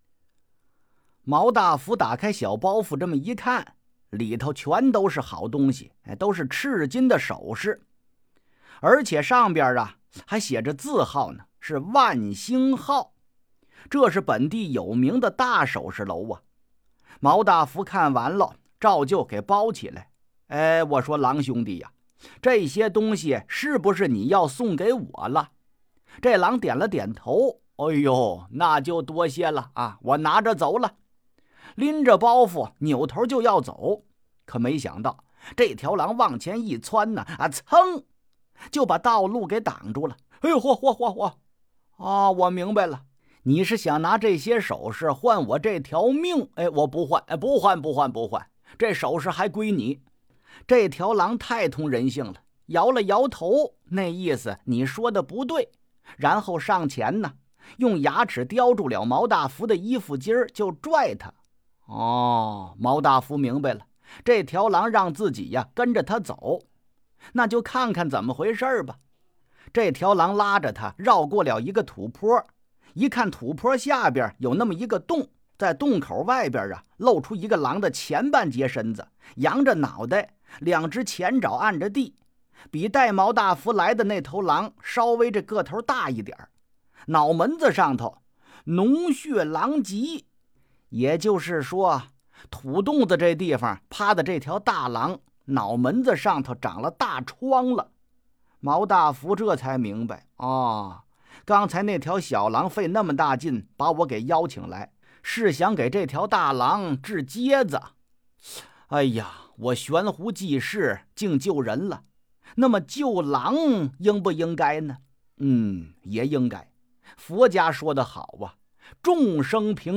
評書『聊斎志異～毛大福』_02.m4a